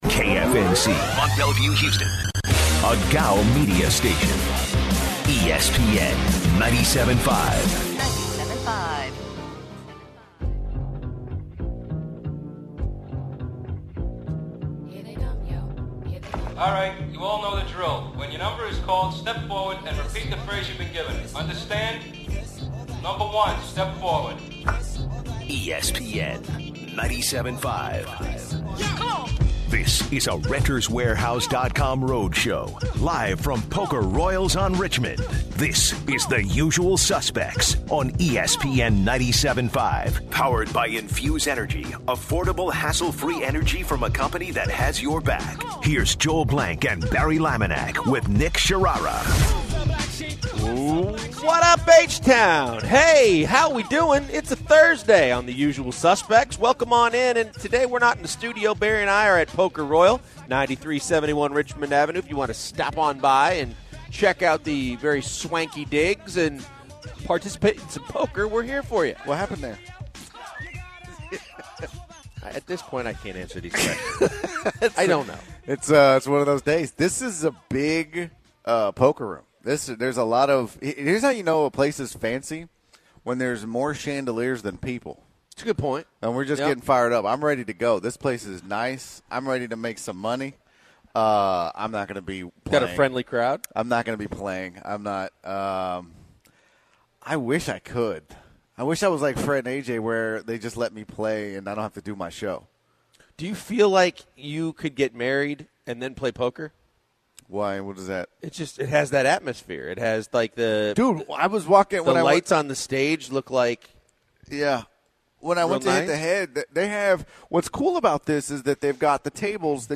on location at Poker Royal